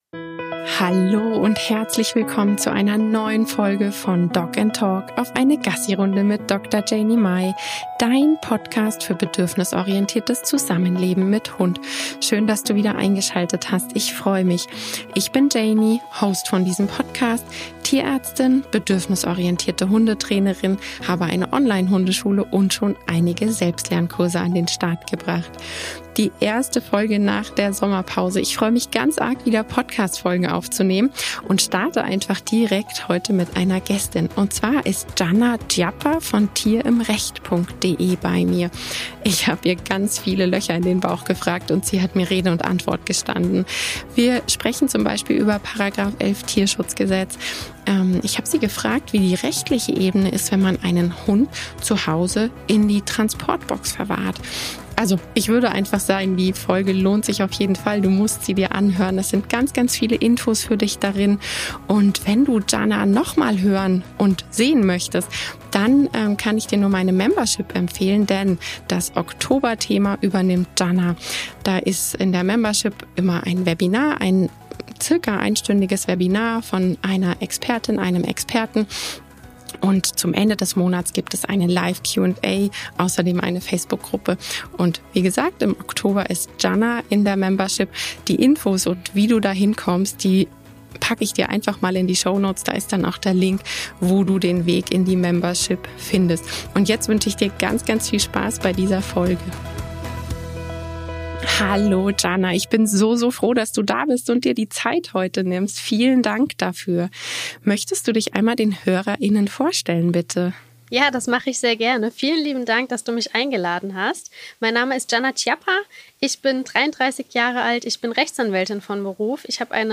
Im Interview habe ich mit ihr über Fragen speziell zum Hund unterhalten: wie sieht es mit dem Verwahren in der Transportbox aus und was kann das Ordnungsamt überhaupt leisten?